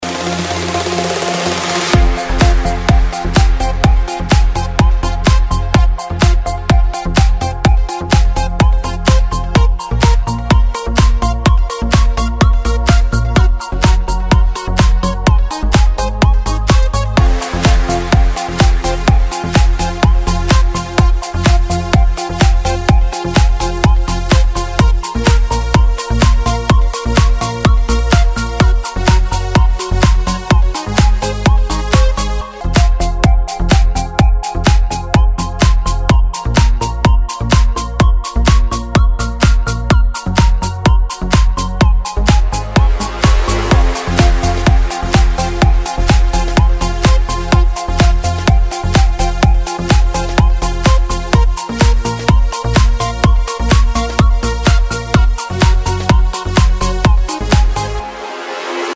• Качество: 320, Stereo
ритмичные
восточные мотивы
энергичные
Trance
звонкие
Восточный транс